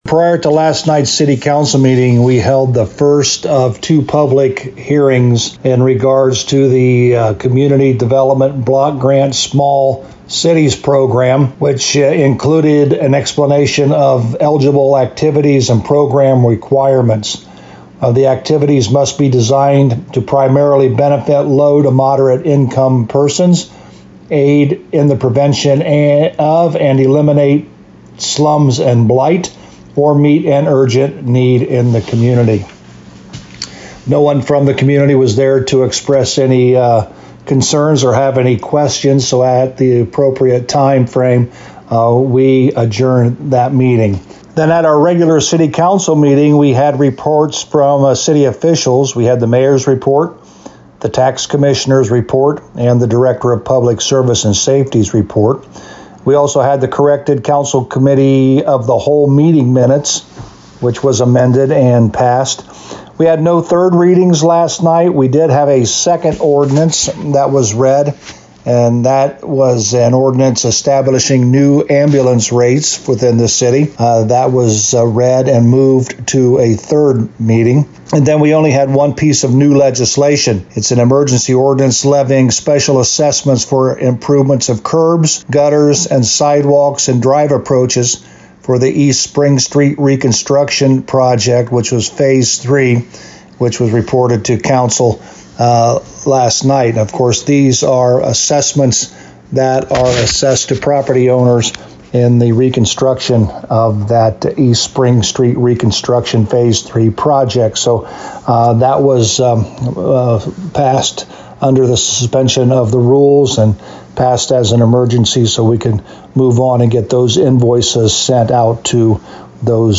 For a summary with Mayor Joe Hurlburt: